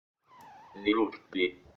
Now, listen for निरुक्ति «nirukti»:
But it sounds  {ni.roak-té}
ni1roak-ta1.mp3